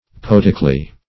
Search Result for " poetically" : Wordnet 3.0 ADVERB (1) 1. in a poetic manner ; - Example: "poetically expressed" The Collaborative International Dictionary of English v.0.48: Poetically \Po*et"ic*al*ly\, adv.